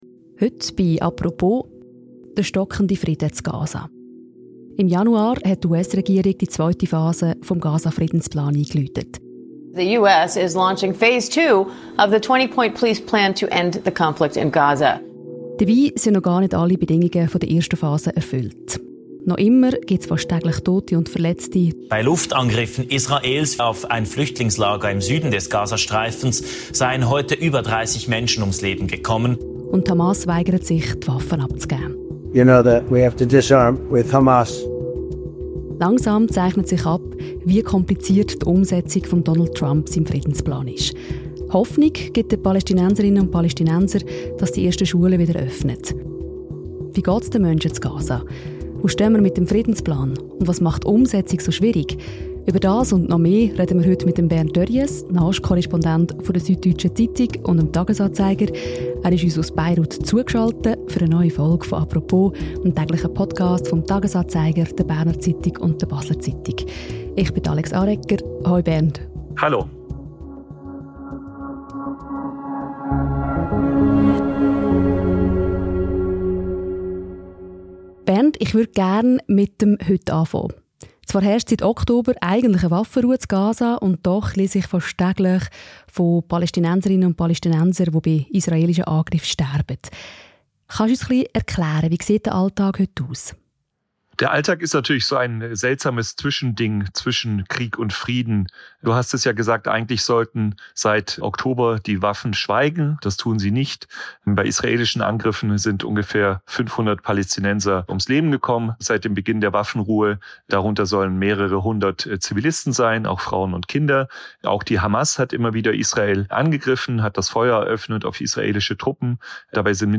Er ist in einer neuen Folge des täglichen Podcasts «Apropos» aus Beirut zugeschaltet.